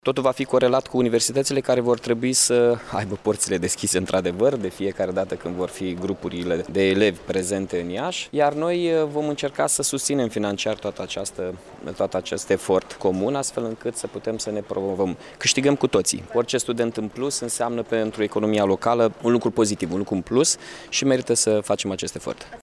În acea perioadă, din zona gării mari, la intervale orare cuprinse între 60 şi 90 de minute vor pleca autobuze către campusurile universitare şi zonele academice ale Iaşiului, după cum a precizat primarul Mihai Chirica: